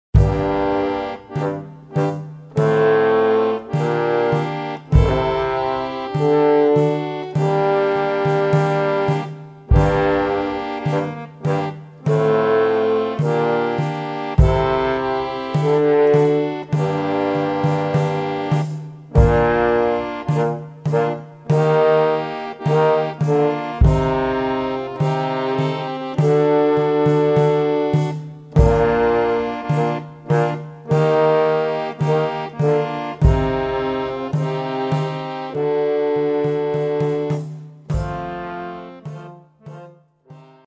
2:19 Harmonium, Posaune, Tambourin, Basstrommel